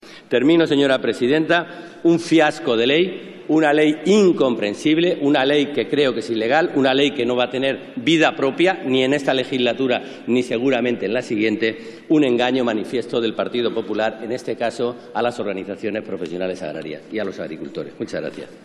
Alejandro Alonso. debate del proyecto de ley por el que se regula el procedimiento para la determinación de la representatividad de las organizaciones profesionales agrarias y se crea el consejo agrario 26-06-14